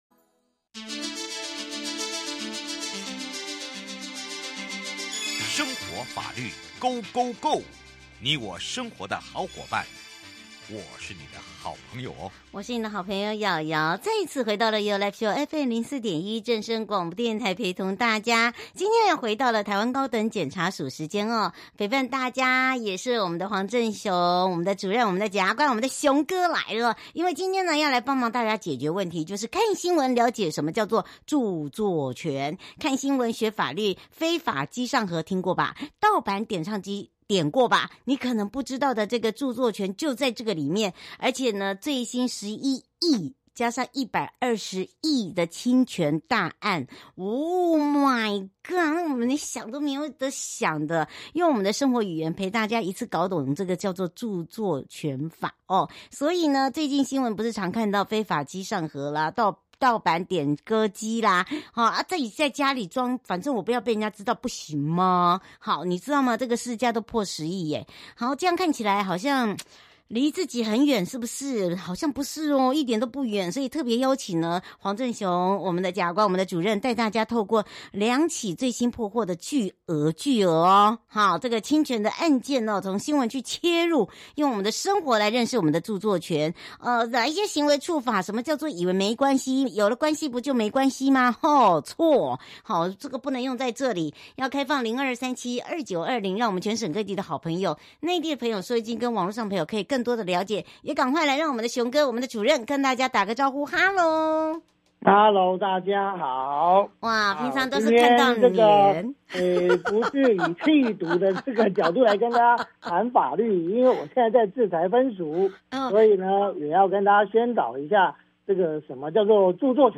受訪者： 臺灣高等檢察署黃正雄檢察官 節目內容： 主題：看新聞了解著作權 「看新聞學法律：非法機上盒、盜版點歌